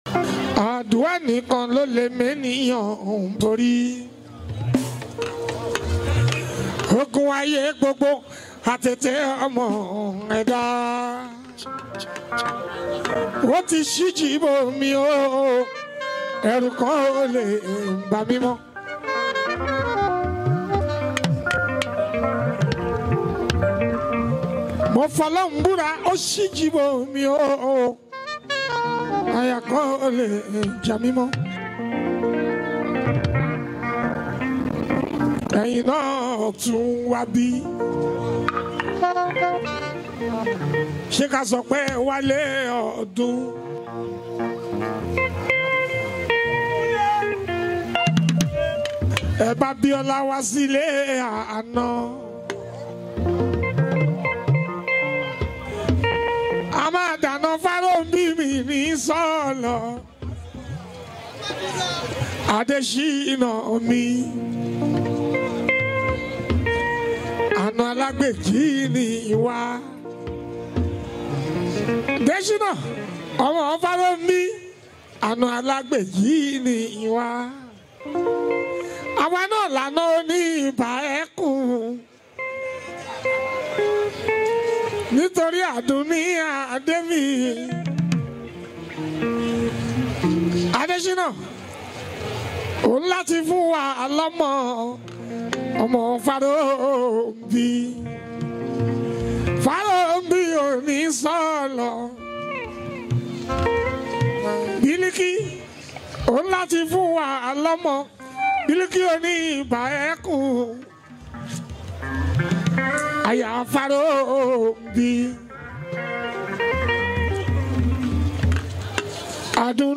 Fuji
Nigerian Yoruba Fuji track
especially if you’re a lover of Yoruba Fuji Sounds